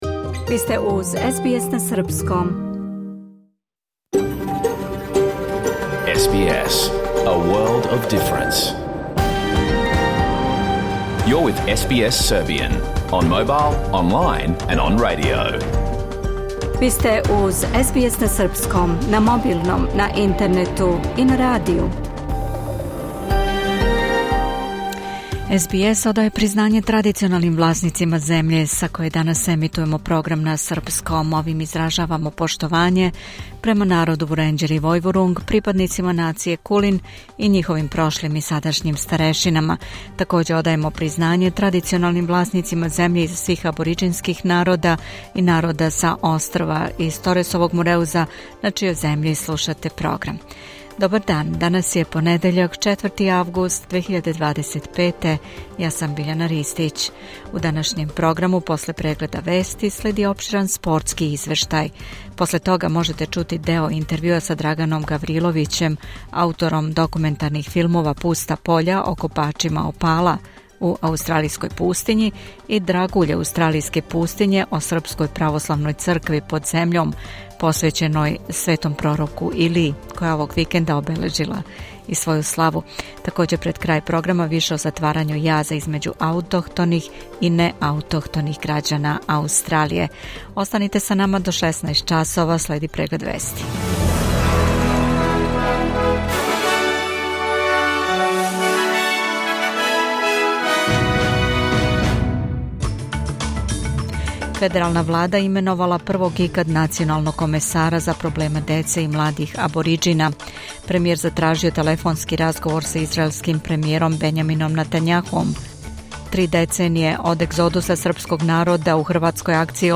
Програм емитован уживо 4. августа 2025. године
SBS Serbian Live